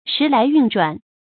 時來運轉 注音： ㄕㄧˊ ㄌㄞˊ ㄧㄨㄣˋ ㄓㄨㄢˋ 讀音讀法： 意思解釋： 時代已成過去；情況有了很大變化。形容境況變好了。